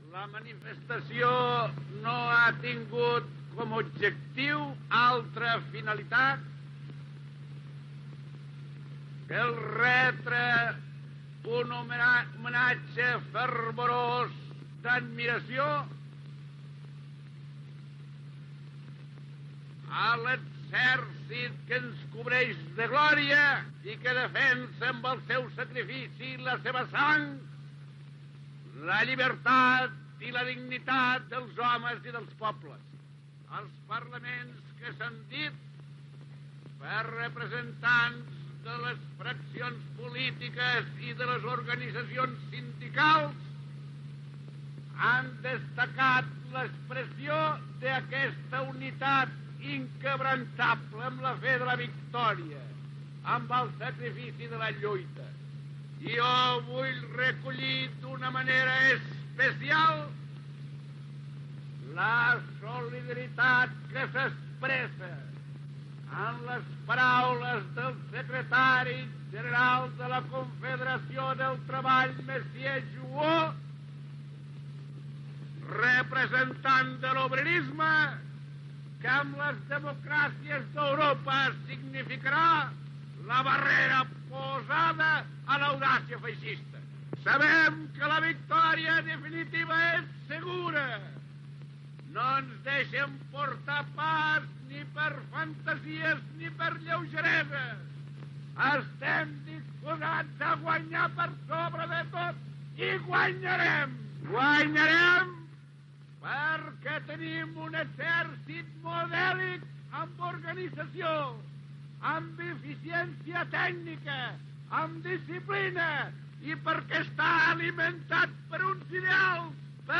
Missatge del president de la Generalitat Lluís Companys a l'exèrcit republicà a la manifestació de Barcelona del Dia del Treballador.
Informatiu
Fragment extret del documental "Lluís Companys 1882-1940" de Televisió Espanyola a Catalunya.